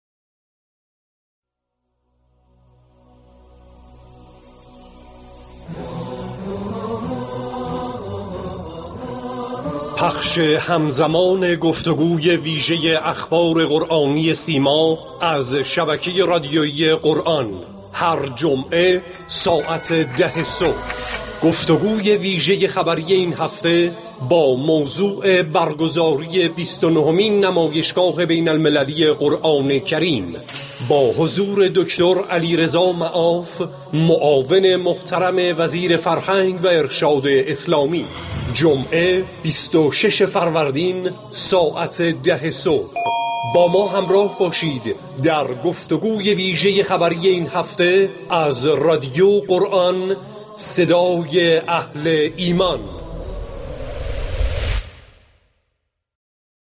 بر اساس این گزارش ، در برنامه روز جمعه 26 فروردین ماه به موضوع برگزاری بیست و نهمین نمایشگاه بین المللی قرآن كریم پرداخته خواهد شد و كارشناس و مهمان این قسمت از برنامه دكتر علیرضا معاف ، معاون محترم وزیر فرهنگ و ارشاد اسلامی می باشد.